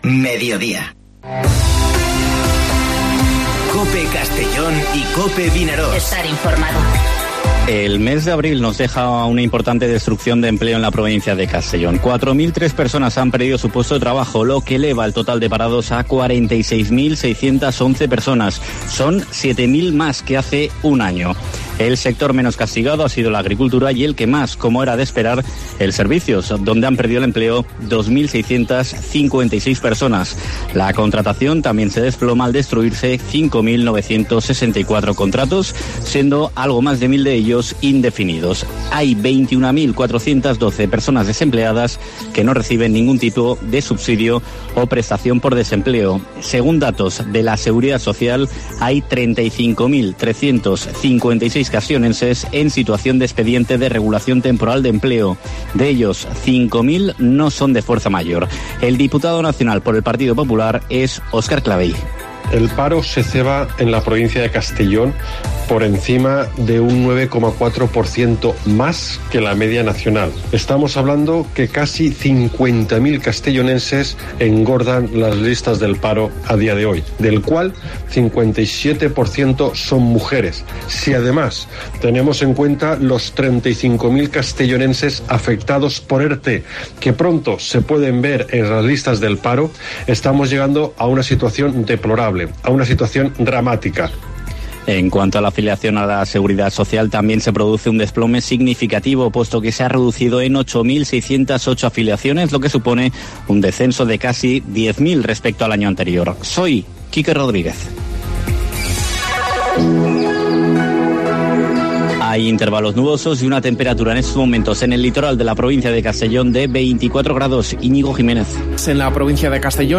Informativo Mediodía COPE en la provincia de Castellón (05/05/2020)